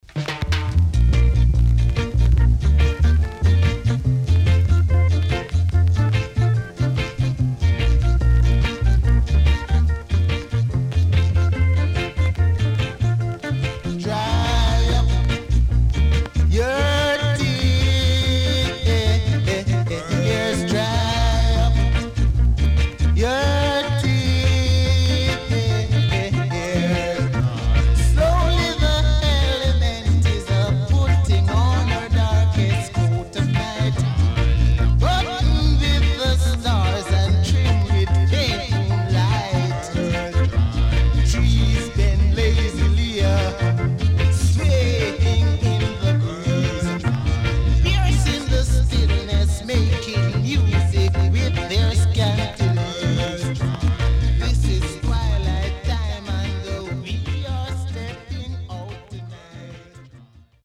EARLY REGGAE
Good Organ Inst & Early Reggae Vocal.W-Side Good
SIDE A:所々チリノイズがあり、少しプチノイズ入ります。